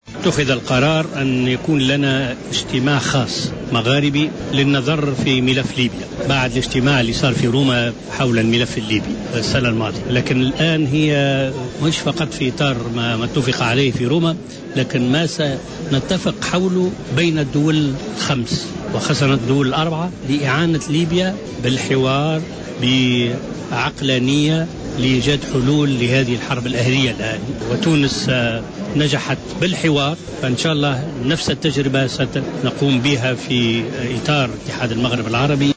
Habib Ben Yahia, secrétaire général de l'Union du Maghreb arabe (UMA) a indiqué lundi 19 mai au miucro de Jawhara Fm, que les pays du grand Maghreb ont décidé d'organiser une réunion d'urgence pour envisager les solutions possibles à la situation de chaos qui prévaut actuellement en Libye.